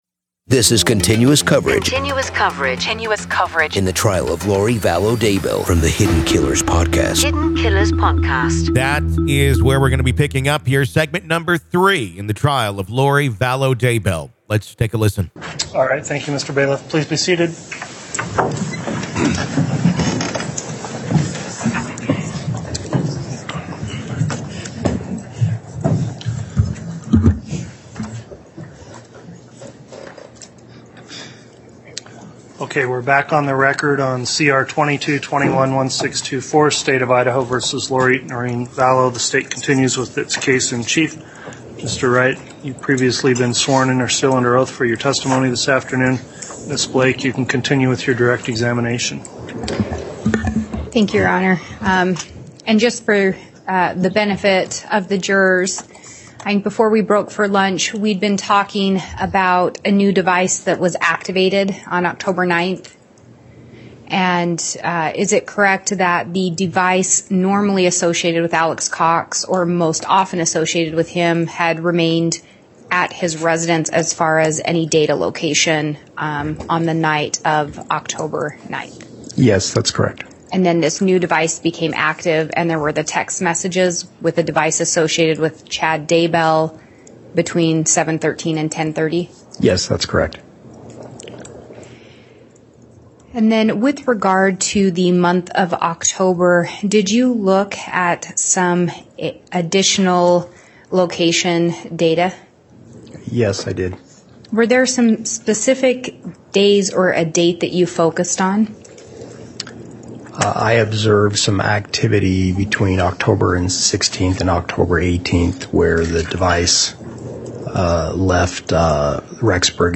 The Trial Of Lori Vallow Daybell Day 14 Part 3 | Raw Courtroom Audio